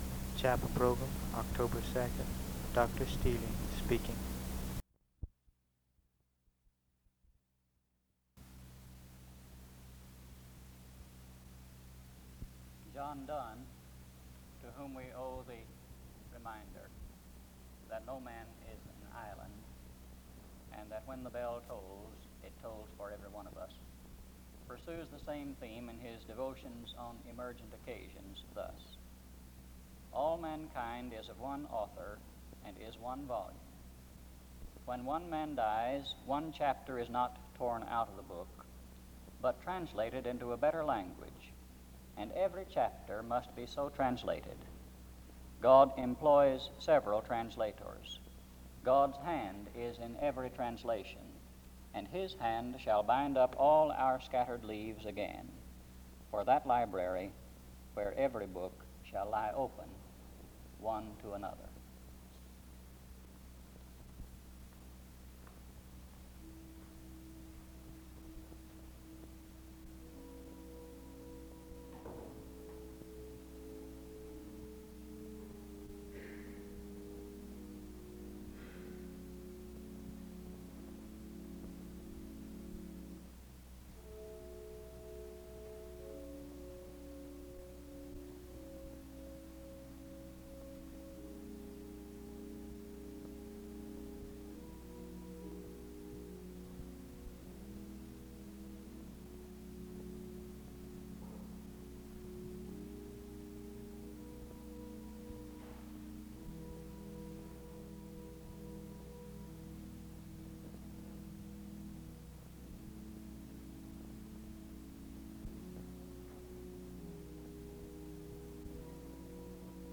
The service begins with a word of contemplation and music from 0:00-5:04. A prayer is offered from 5:15-9:16. A responsive reading takes place from 9:26-10:56. Music plays from 11:03-16:05.